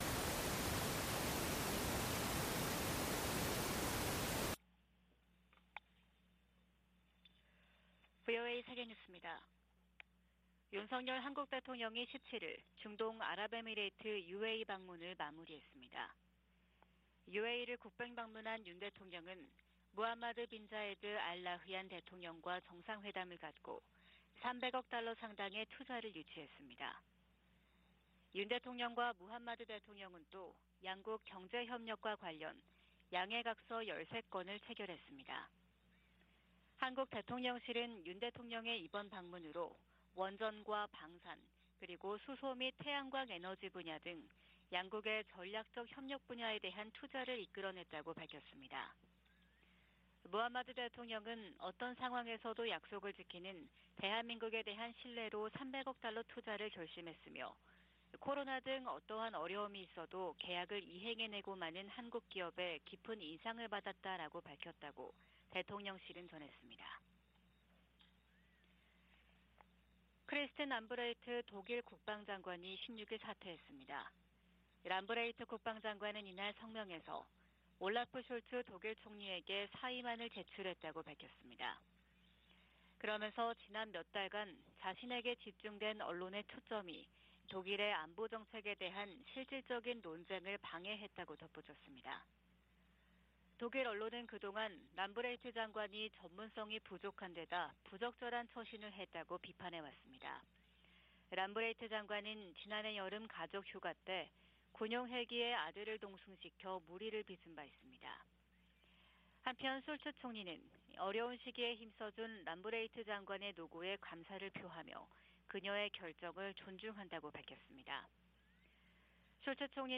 VOA 한국어 '출발 뉴스 쇼', 2023년 1월 18일 방송입니다. 미국 해군참모총장은 한국의 ‘자체 핵무장’ 안과 관련해 미국의 확장억제 강화를 현실적 대안으로 제시했습니다. 유엔은 핵보유국 의지를 재확인한 북한에 긴장 완화를 촉구하고 유엔 결의 이행과 외교를 북핵 문제의 해법으로 거듭 제시했습니다.